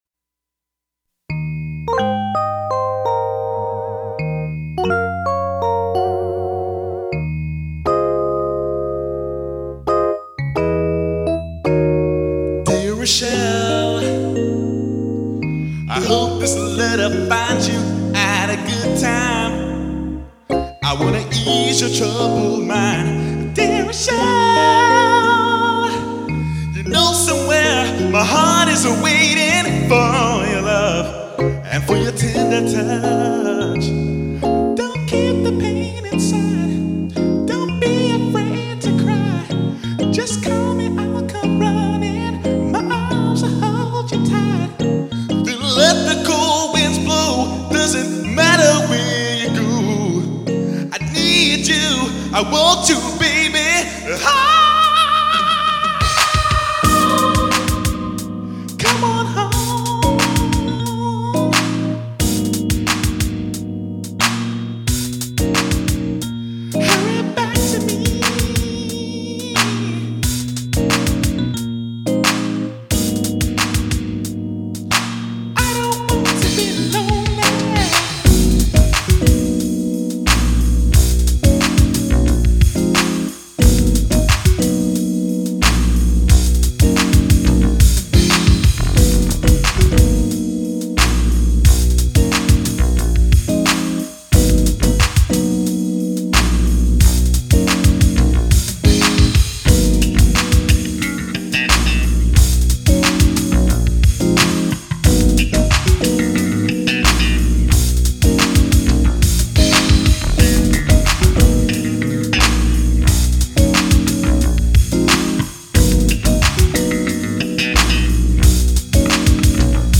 This song also marked the introduction of my new Yamaha 1204 mixing board.
The production values in my little studio shot way up, as I clumsily learned how to use the board by trial and error.
bass